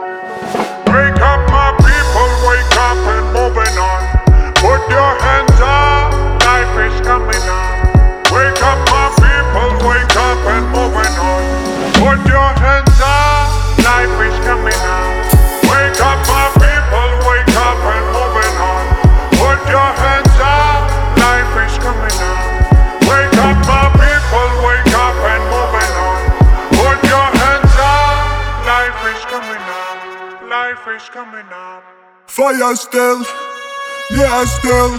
Жанр: Реггетон / Русские